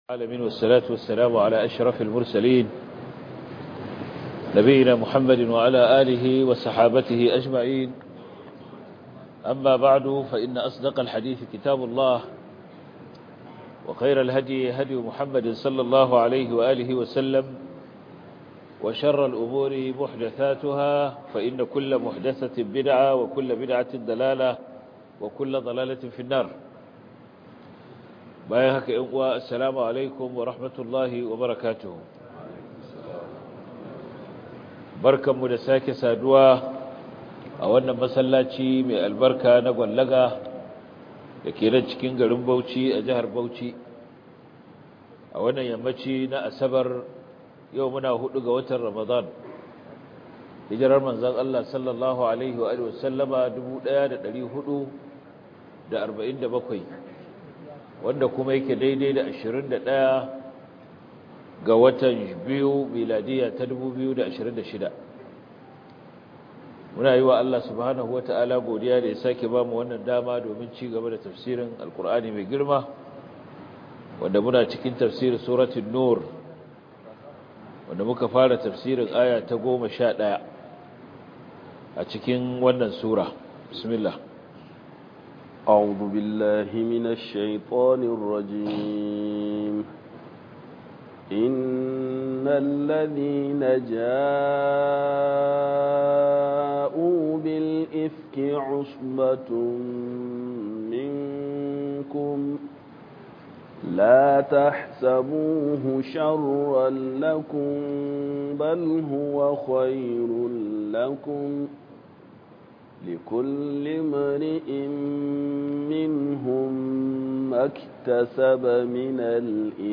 ← Back to Audio Lectures 04 Ramadan Copied!
Tafsir